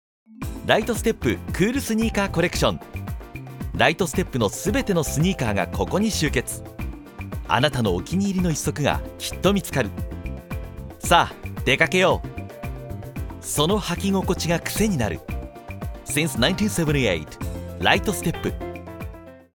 Amical
Jeune